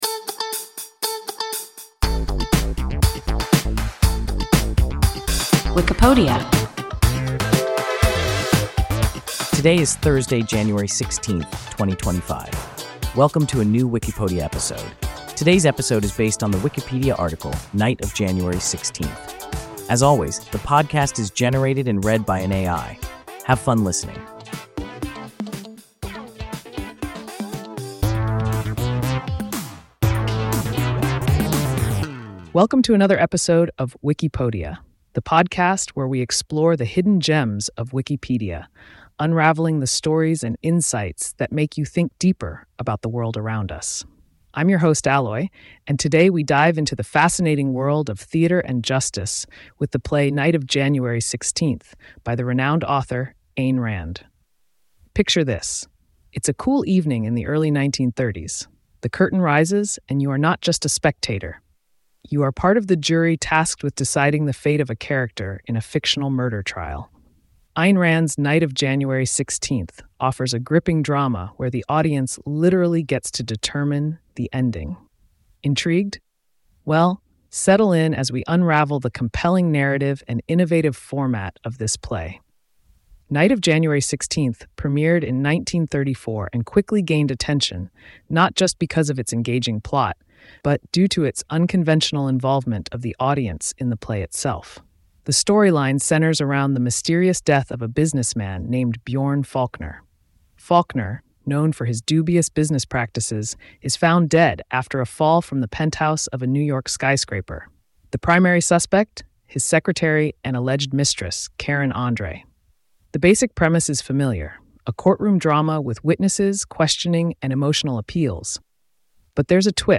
Night of January 16th – WIKIPODIA – ein KI Podcast